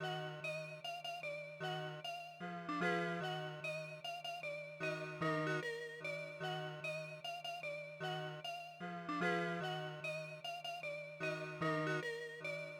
150BPM travis scott type melody flipped.wav